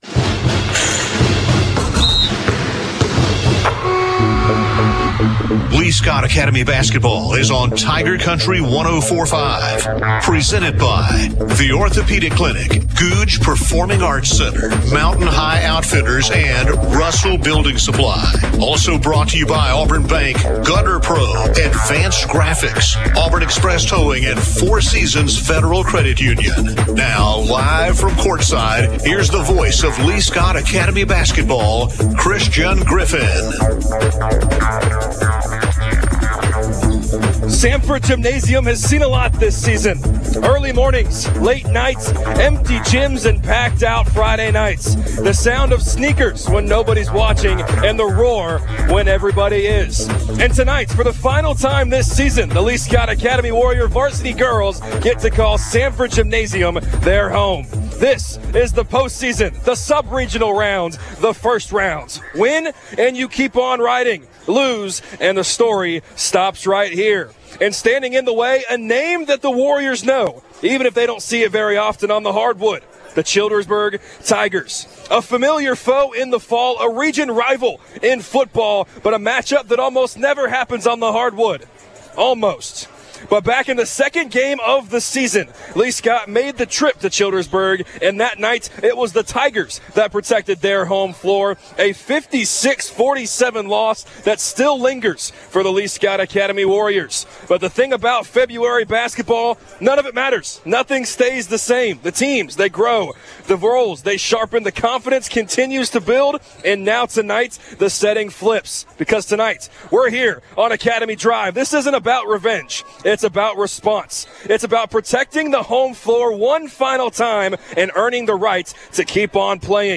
calls Lee-Scott Academy's game against Childersburg in the State Tournament Sub-Regional. The Warriors won 48-46.